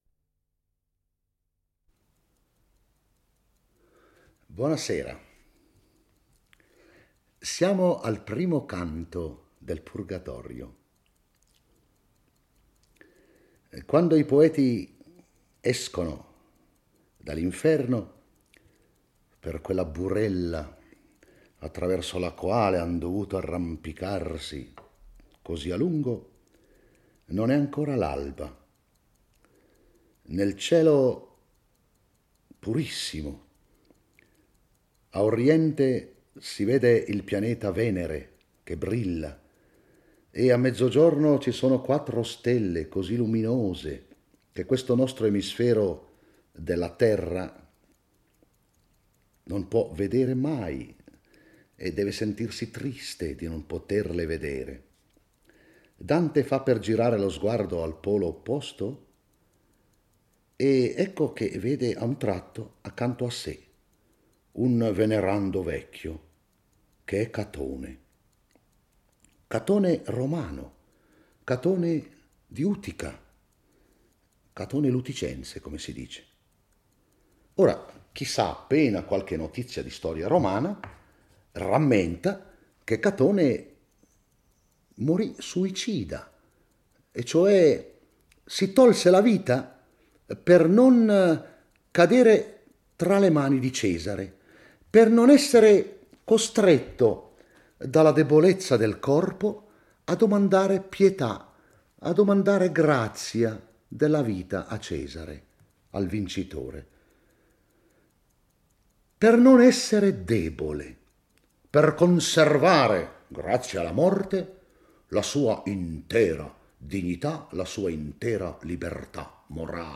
legge e commenta il I canto del Purgatorio. Dante e Virgilio abbandonano l'Inferno e approdano sulla spiaggia dell'Antipurgatorio di cui Catone l'Uticense è il custode. Tutto il canto gira attorno al tema principale della libertà morale; Catone, un pagano suicidatosi nel 46 a.C per non soccombere alla tirannide di Cesare, ne è l'emblema.